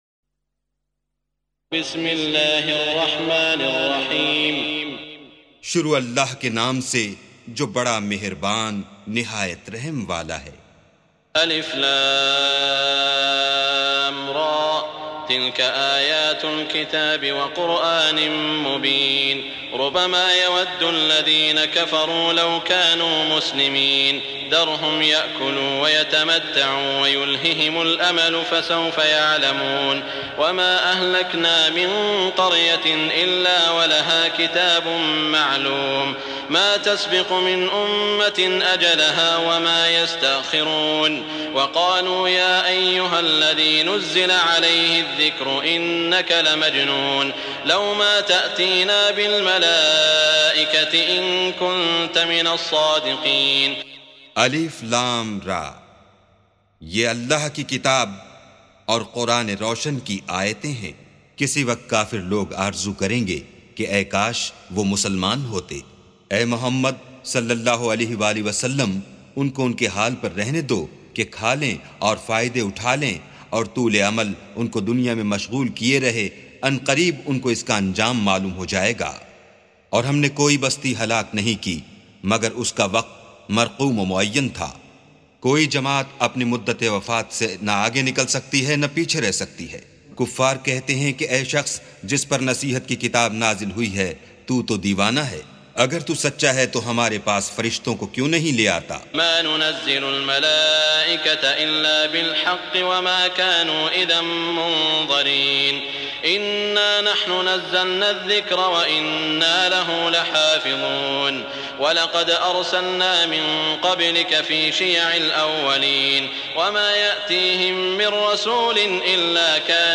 سُورَةُ الحِجۡرِ بصوت الشيخ السديس والشريم مترجم إلى الاردو